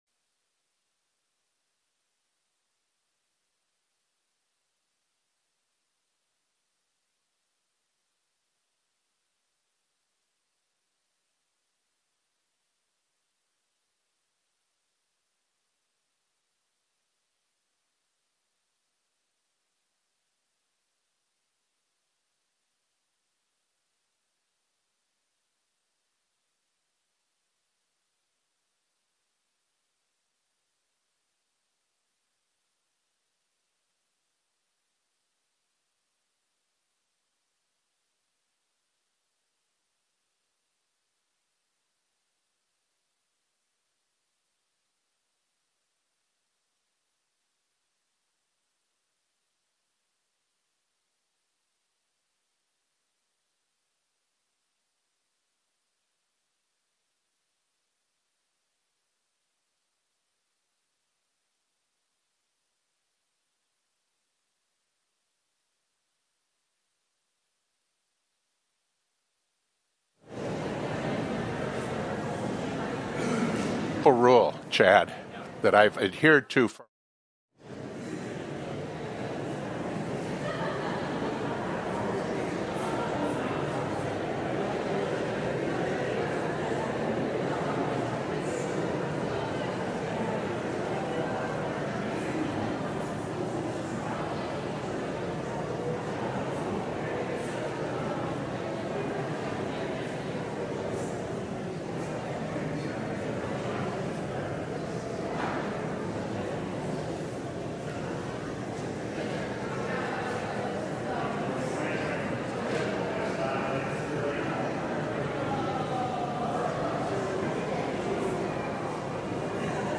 LIVE Morning Worship Service - Following Jesus
Congregational singing—of both traditional hymns and newer ones—is typically supported by our pipe organ.